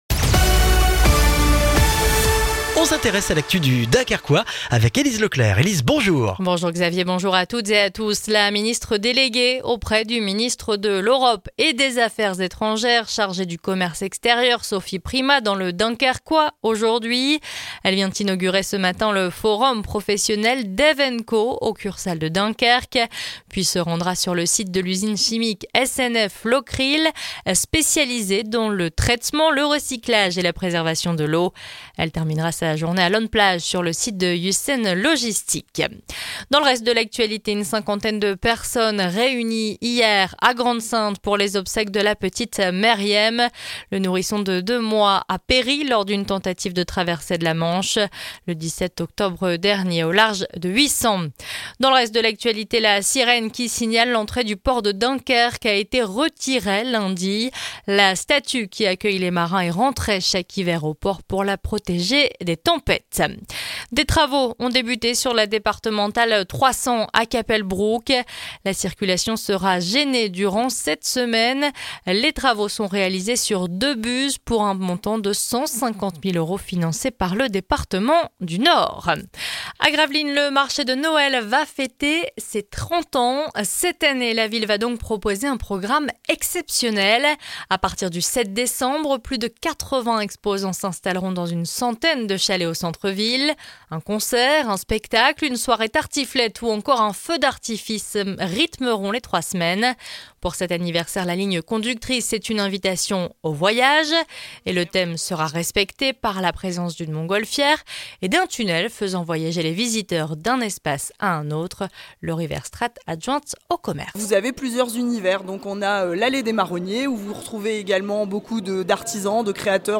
Le journal du mercredi 13 novembre dans le Dunkerquois